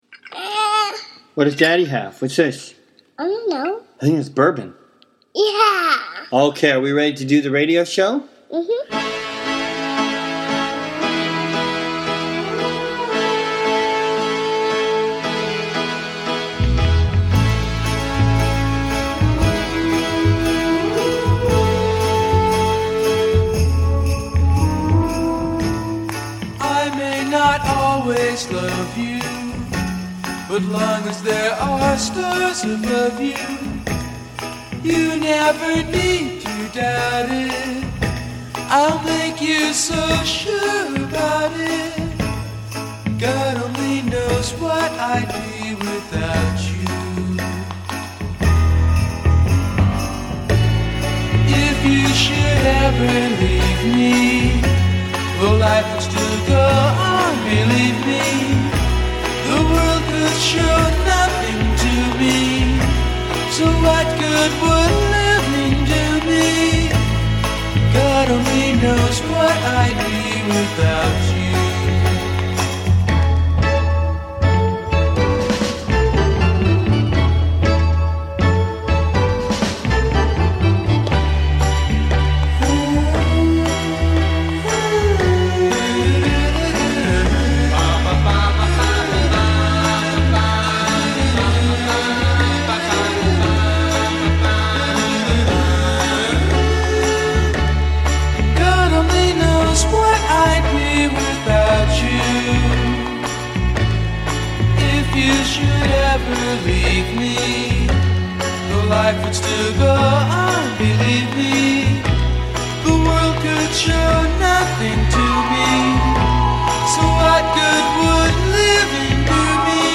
Have a download of my most recent mixtape.